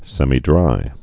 (sĕmē-drī, sĕmī-)